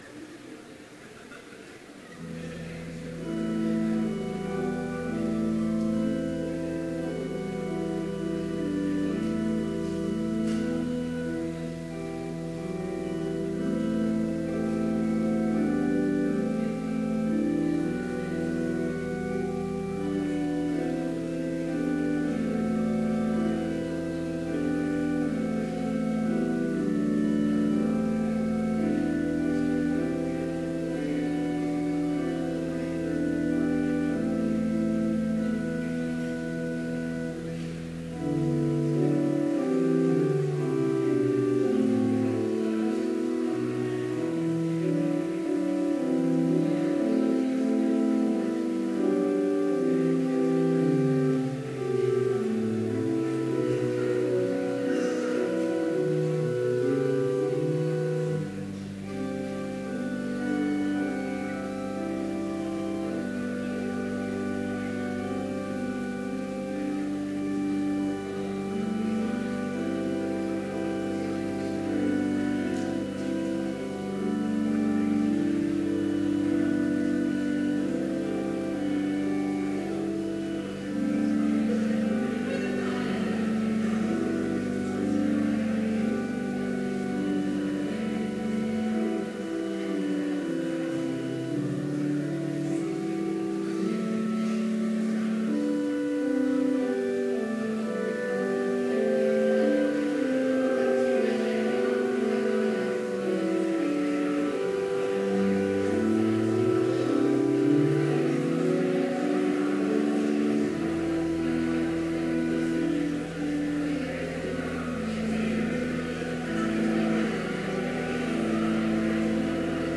Complete service audio for Chapel - March 1, 2017